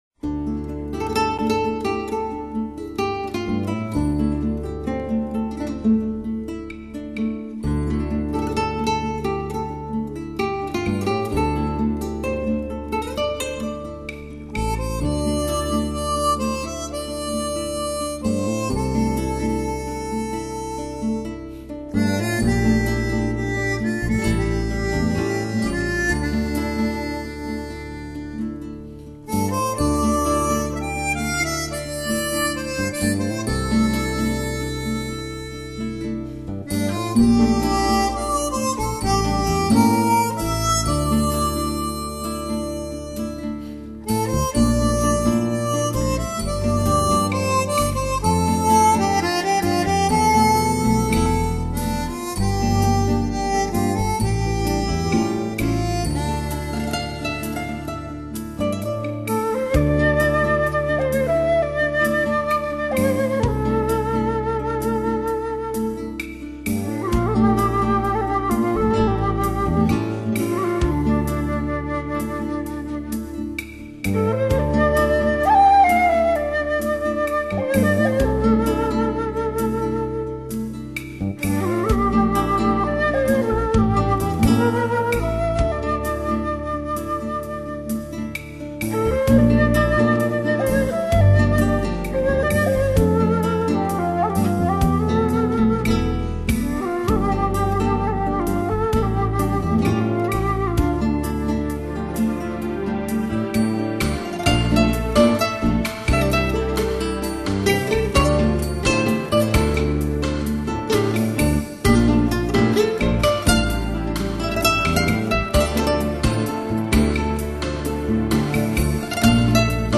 清风由远而近轻拂著脸庞、潺潺溪水从指尖滑过、樱花落英缤纷轻洒肩上、优雅清新乐章飘扬耳际间、、、